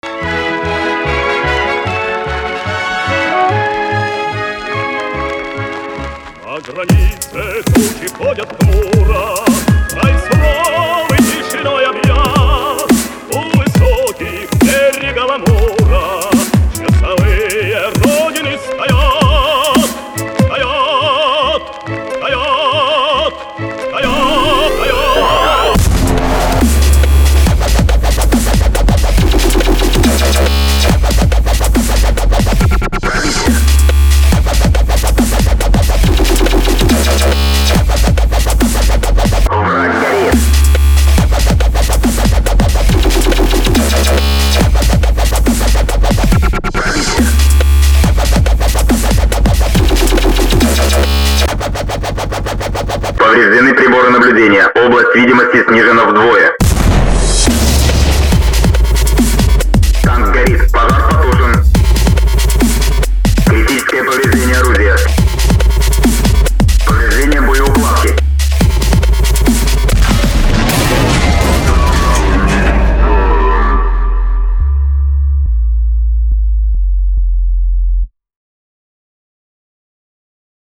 Dub Step для конкурса онлайн игры World of Tanks.